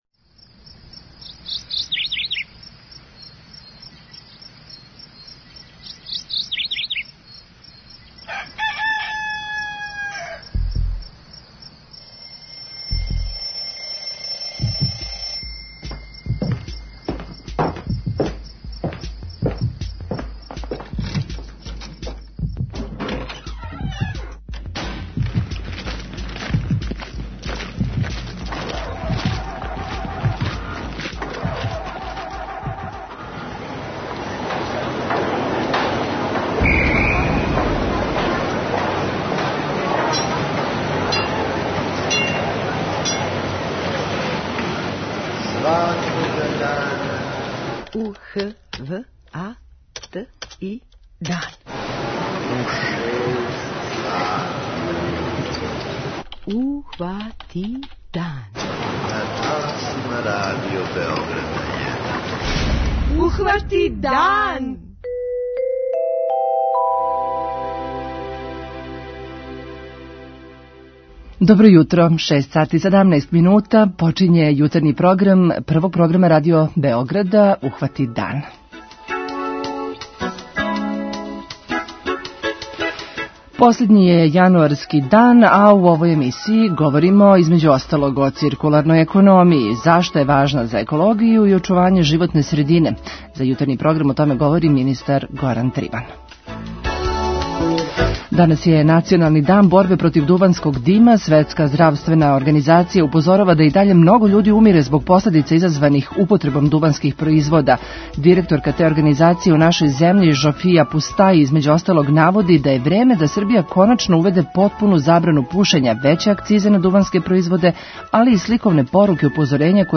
У Питању јутра са вама разговарамо о томе да ли треба увести потпуну забрану пушења на јавним местима.